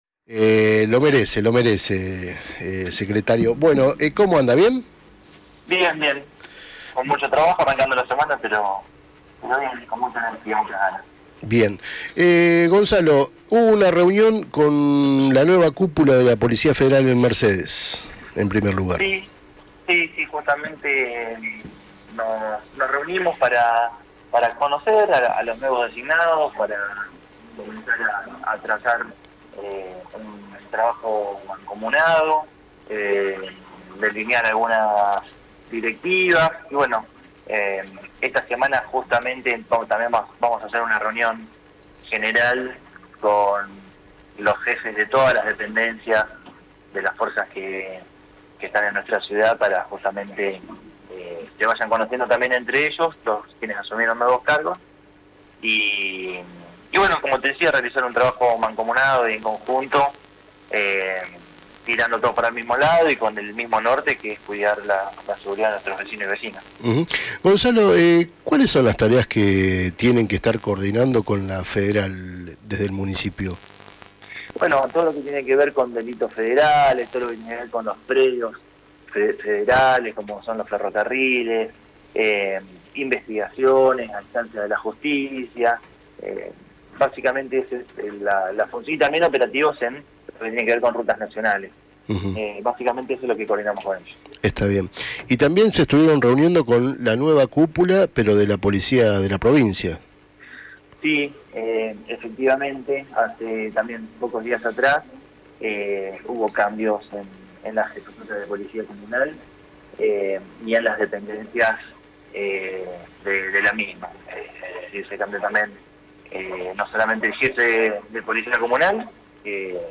El secretario de Seguridad de la Municipalidad de mercedes, Gonzalo Anselmo, habló durante la mañana de hoy en el primer programa de DatoPosta Radio en FM La Tribuna